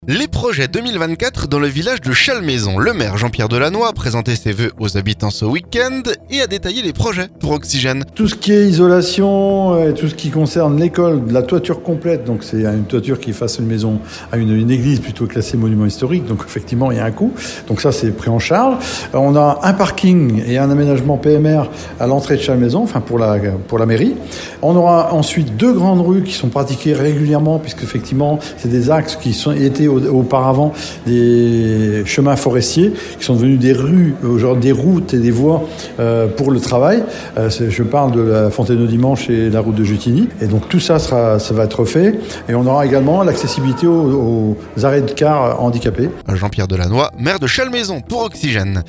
Côté animations, la course La Savonaise sera maintenue cette année. Et d'autres projets qu'il détaille pour Oxygène.